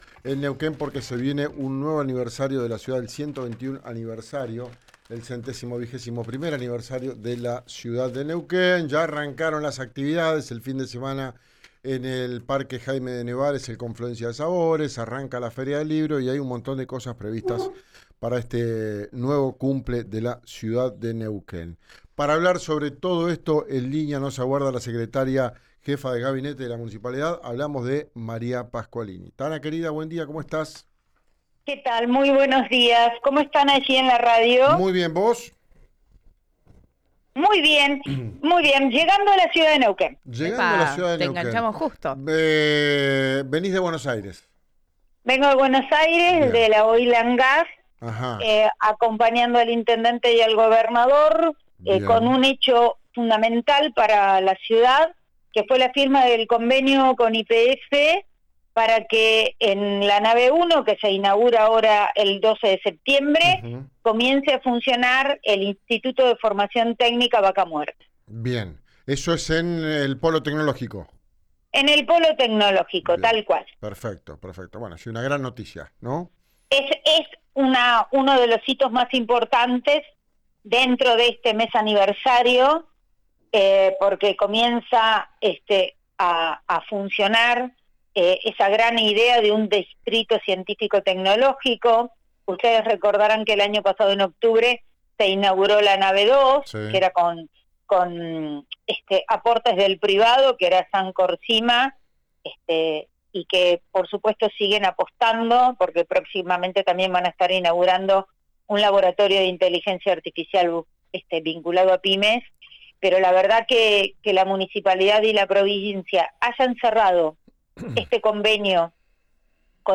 La fecha fue confirmada para febrero, y en diálogo con RÍO NEGRO RADIO, María Pasqualini, secretaria de la Jefatura de Gabinete, brindó detalles sobre lo que se viene. Entradas, sorteo, presentación oficial y ¿artistas confirmados?